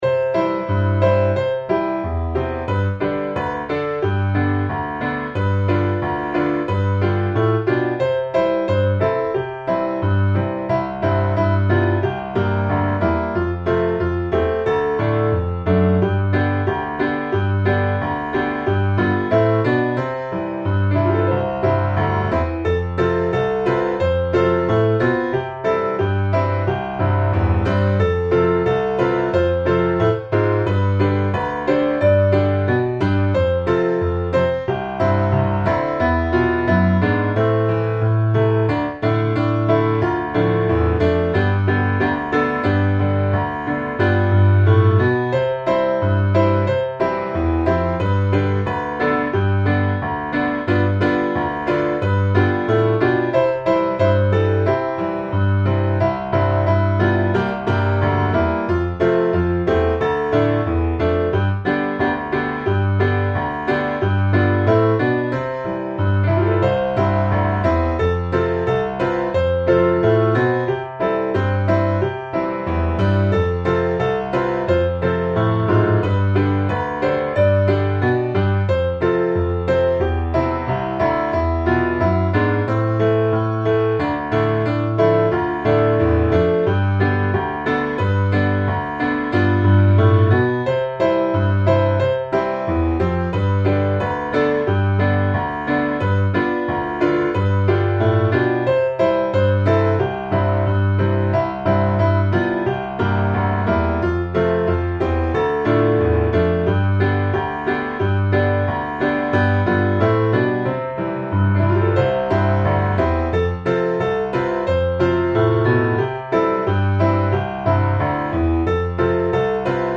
C大調